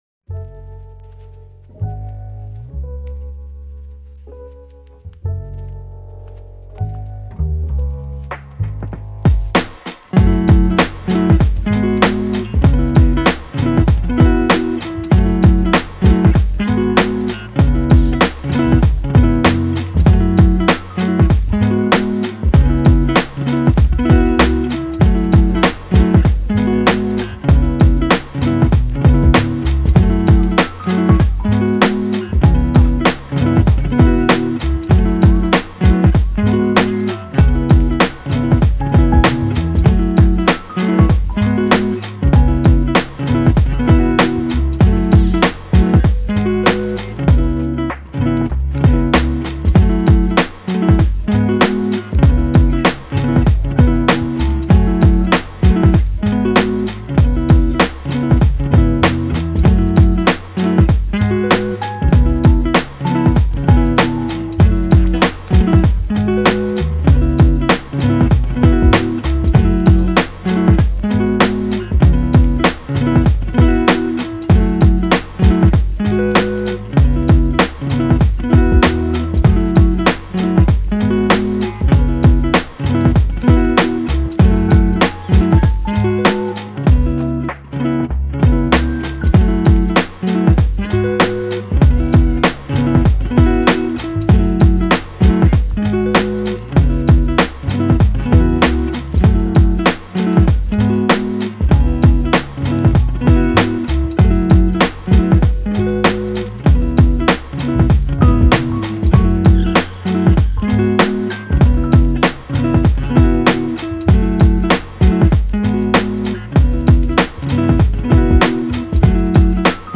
チル・穏やか